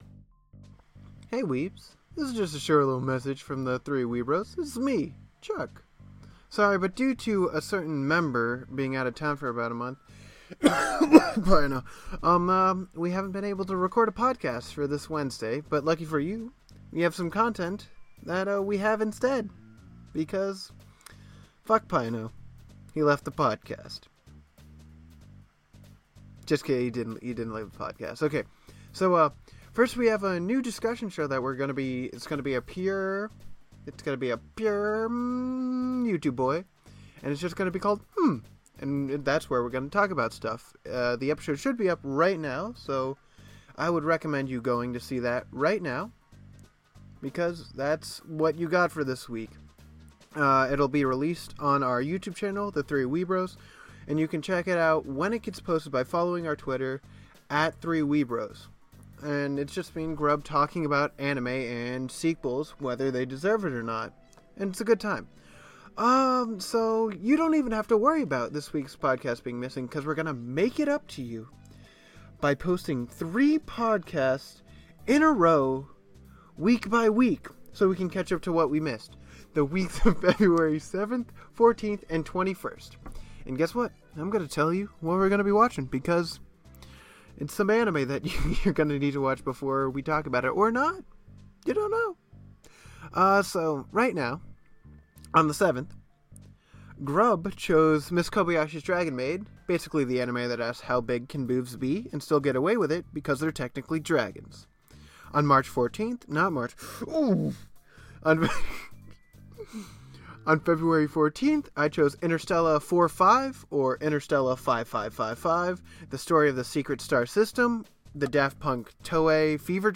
rambling for 3 minutes.